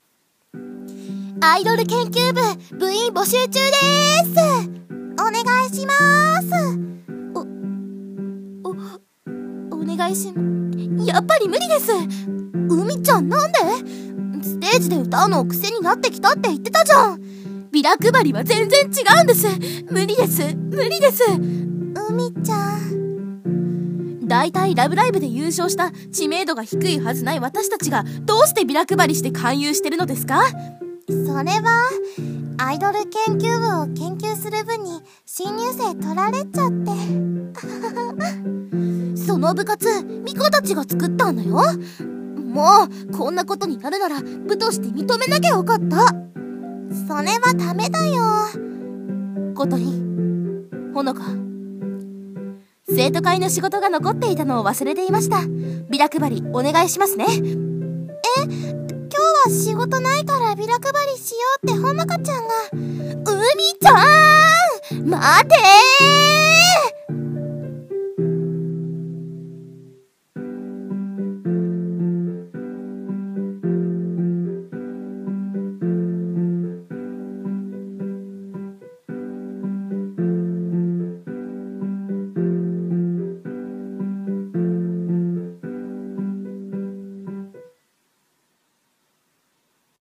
コラボ募集！ラブライブ！声劇！